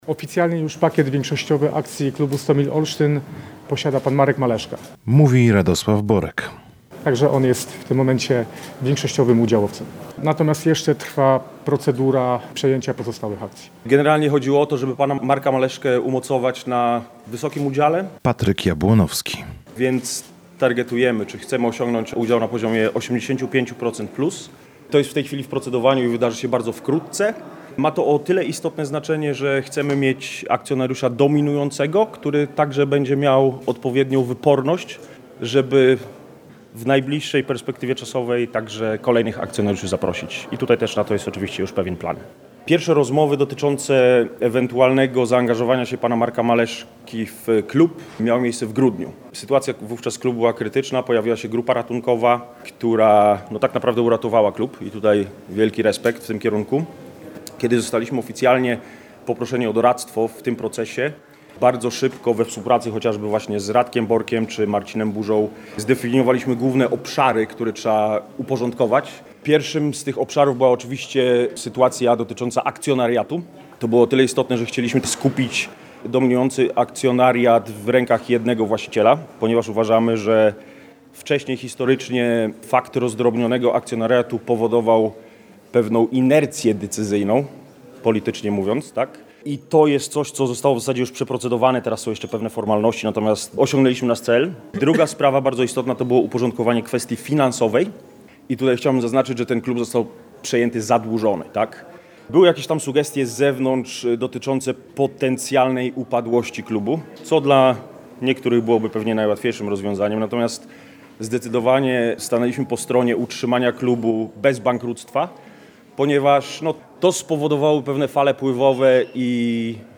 – tłumaczył dziennikarzom obecnym na konferencji